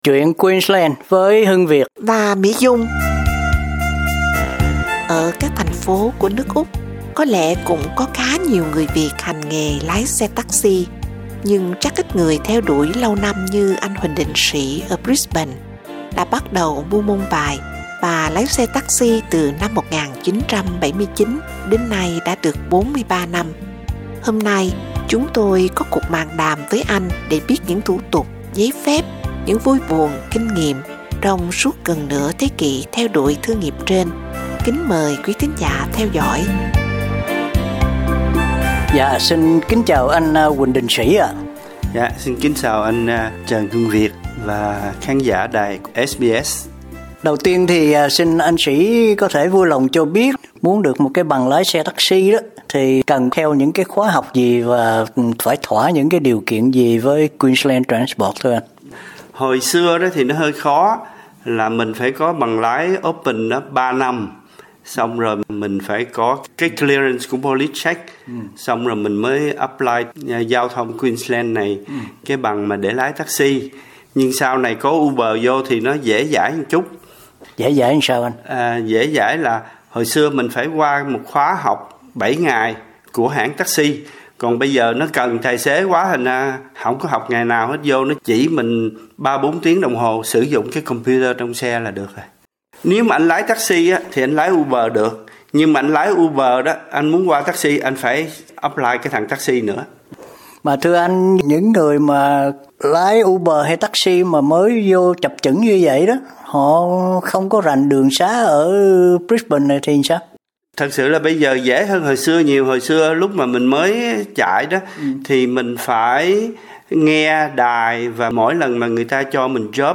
Hôm nay, chúng tôi có cuộc mạn đàm với anh để biết những thủ tục giấy phép, những vui buồn, kinh nghiệm trong suốt gần nửa thế kỷ theo đuổi thương nghiệp trên.